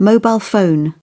mobile phone.mp3